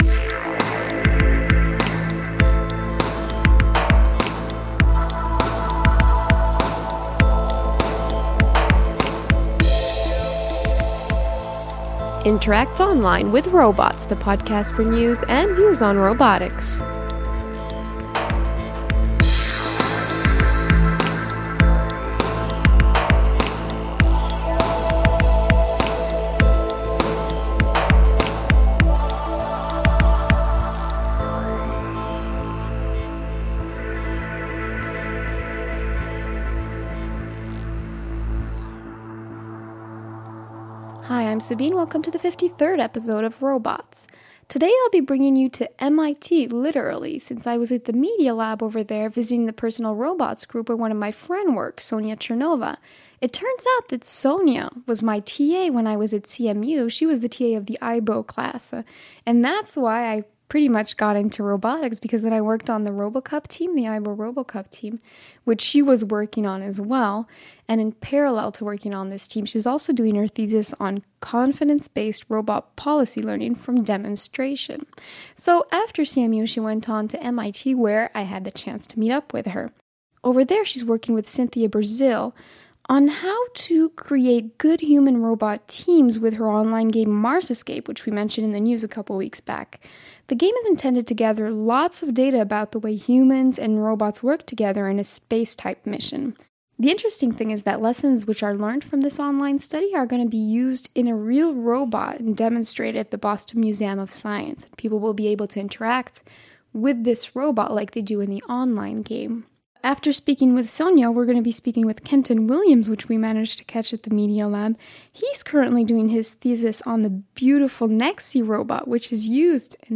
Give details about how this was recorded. Today’s episode was recorded at the Personal Robots Group at the MIT Media Lab in the middle of some beautifully expressive robots such as the Huggable, Tofu and Leonardo.